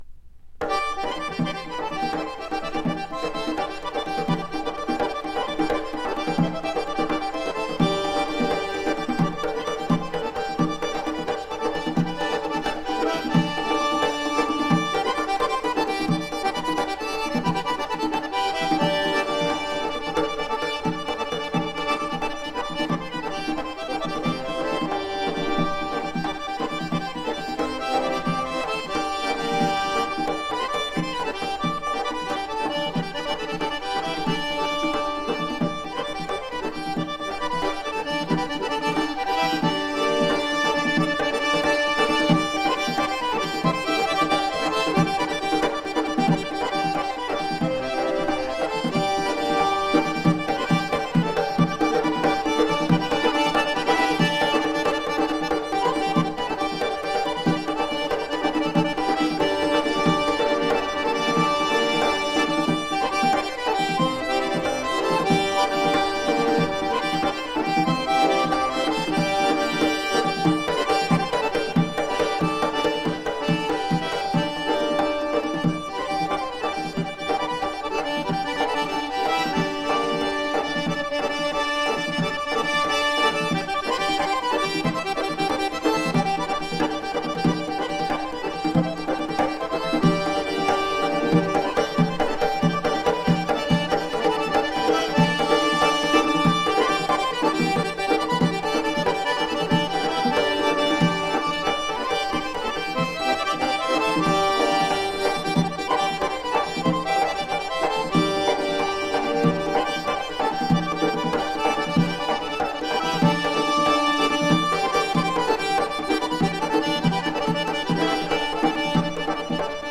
Адыгская музыка